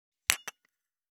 275,皿が当たる音,皿の音,台所音,皿を重ねる,カチャ,ガチャン,